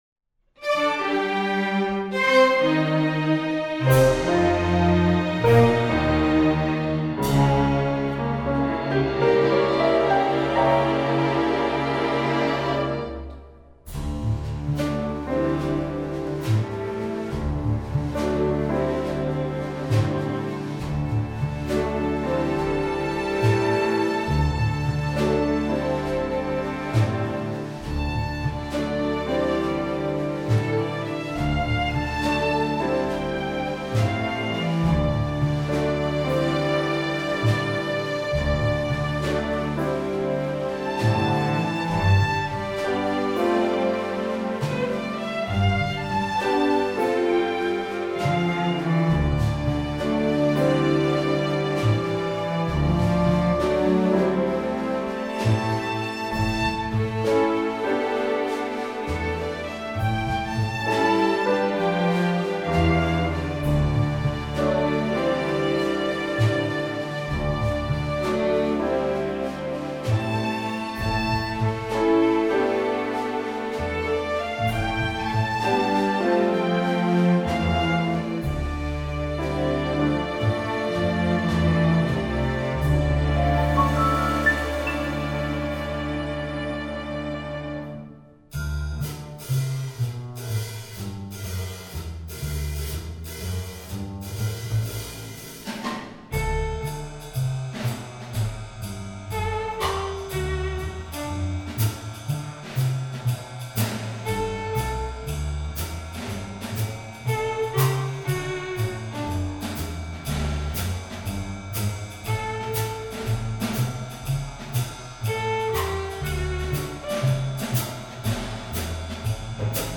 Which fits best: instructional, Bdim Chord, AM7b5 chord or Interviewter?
instructional